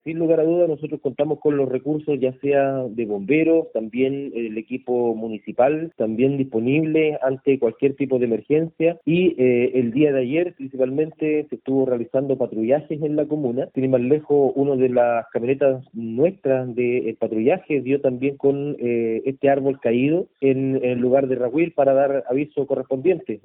El alcalde de Florida, Rodrigo Montero, aseguró que en la comuna están preparados para enfrentar este tipo de emergencias. En esa línea, ya han realizado simulacros en algunos sectores, y también patrullajes.
cuna-florida-alcalde.mp3